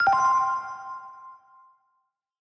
UI_Area_Enter_WhiteZone.ogg